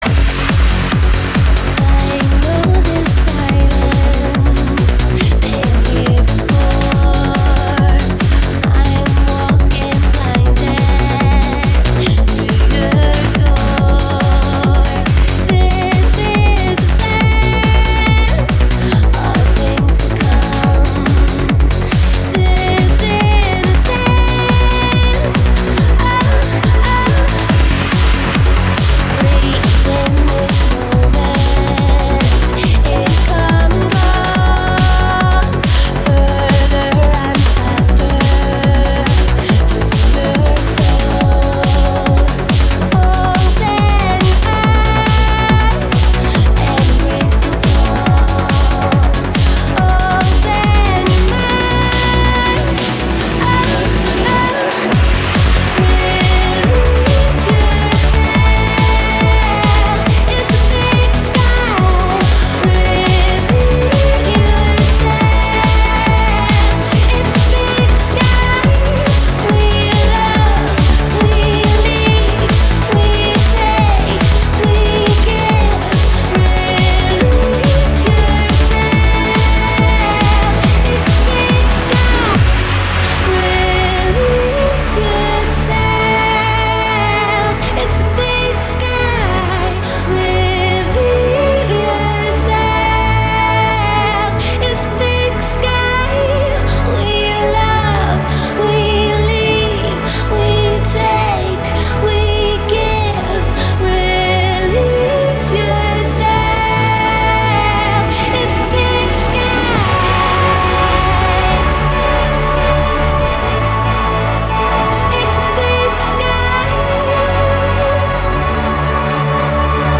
Жанр: Trance